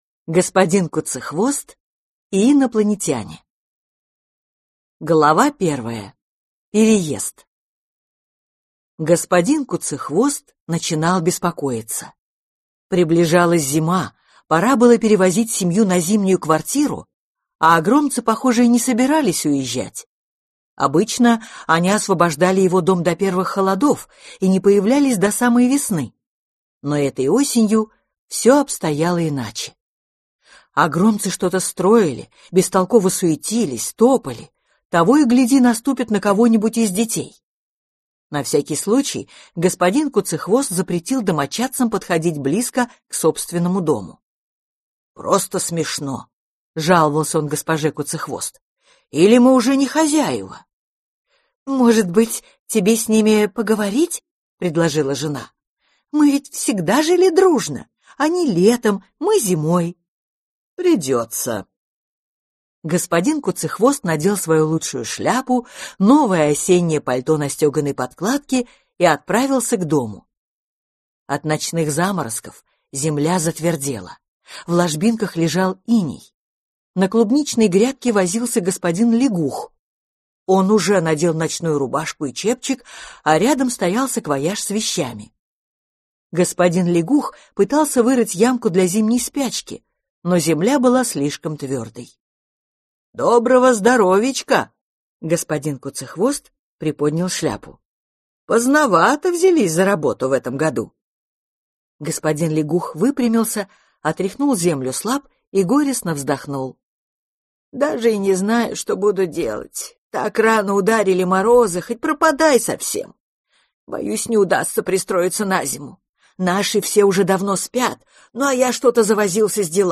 Аудиокнига Господин Куцехвост и инопланетяне | Библиотека аудиокниг